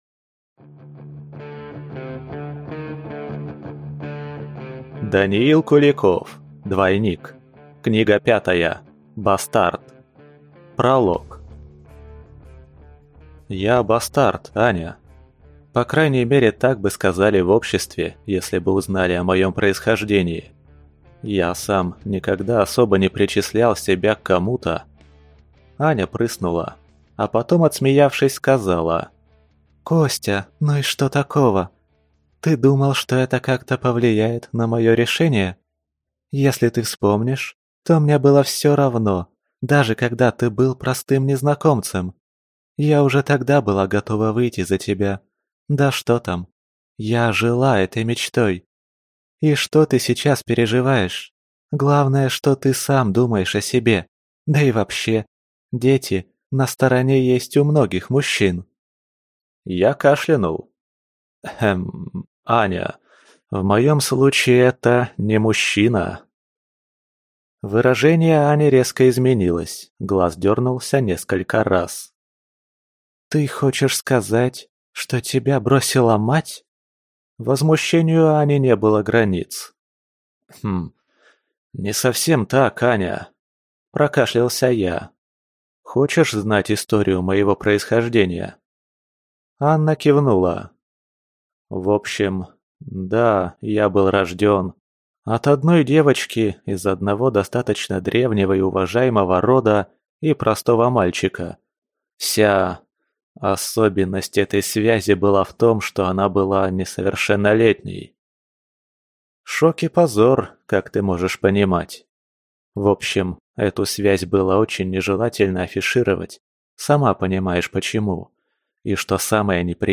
Аудиокнига Двойник. Книга 5. Бастард | Библиотека аудиокниг